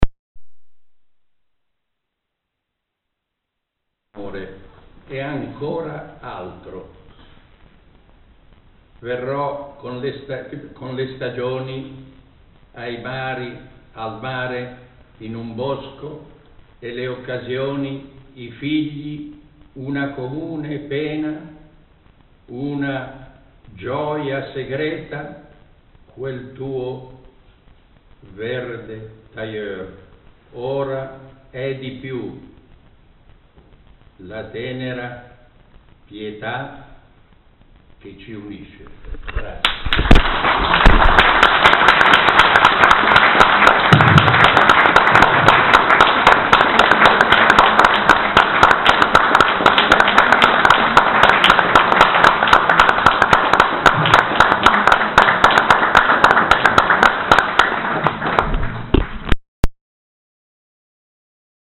05 5. lettura del libraio antiquario.mp3